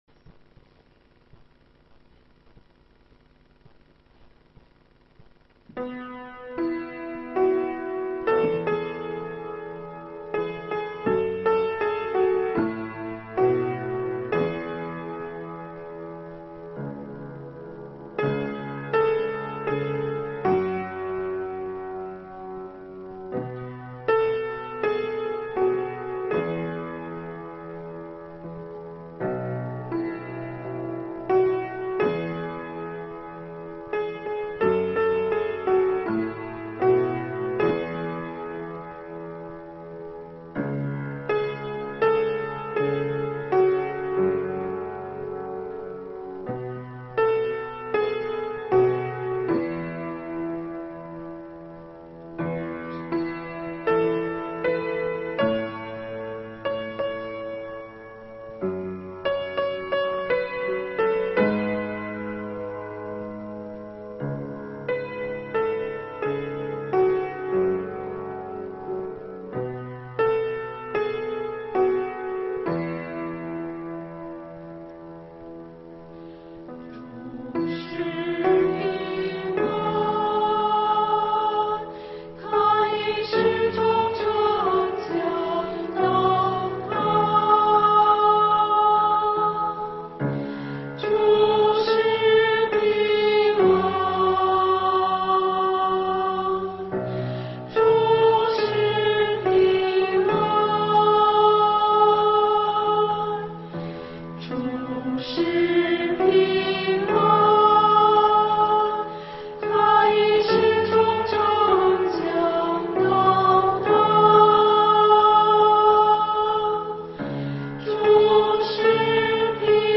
证道内容： 希伯来书第7章可以分成两个大的段落来理解。